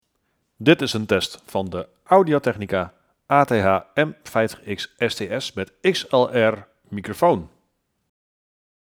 We hebben dan ook een opname gemaakt zonder additionele software of hardwarematige trucjes (denk aan ruisonderdrukking) en we waren vooral onder de indruk van de helderheid, warmte en vrijwel geruisloze opname die de microfoon weet neer te zetten.
Tot dusver is de beste microfoon die we zijn tegengekomen op een headset, dus daar kunnen we alleen maar erg blij van worden.
ATH-M50xSTS-audioopname.mp3